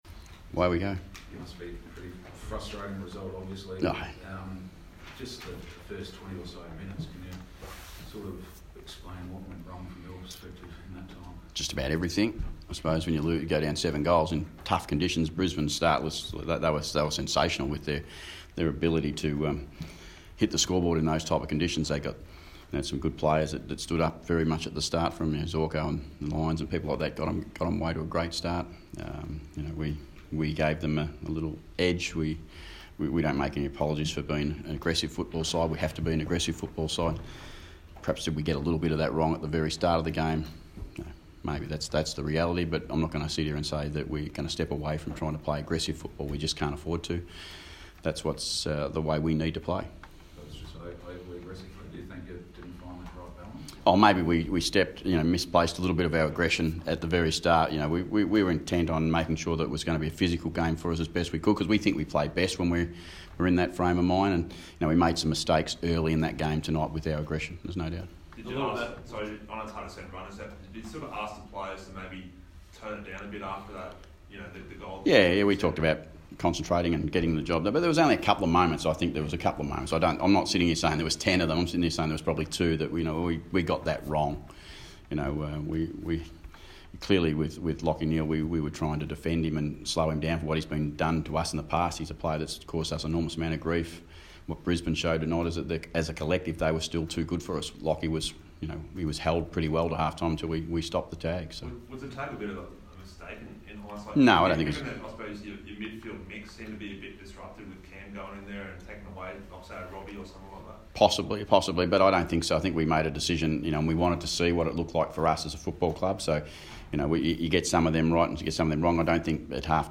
Ken Hinkley press conference - Sunday 14 July, 2019